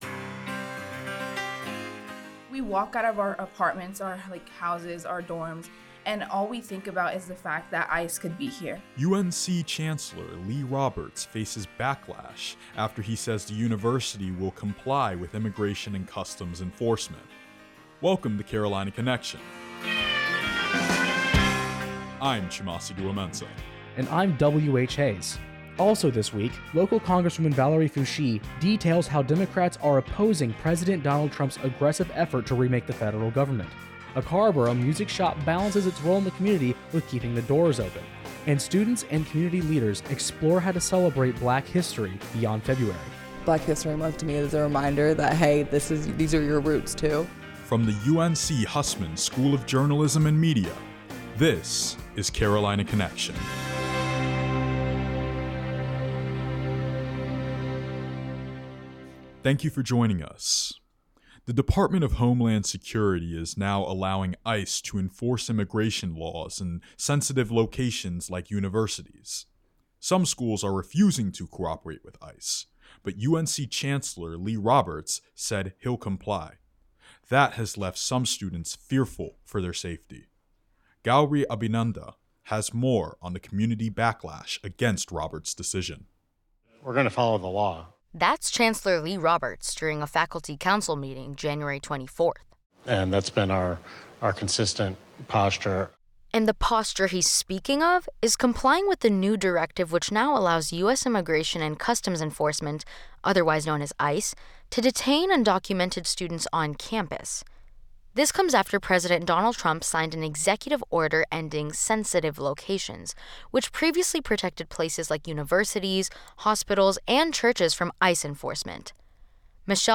Student radio from the University of North Carolina Hussman School of Journalism and Media